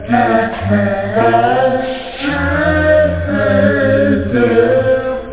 Amiga 8-bit Sampled Voice
1 channel
vocals.to.little.move.mp3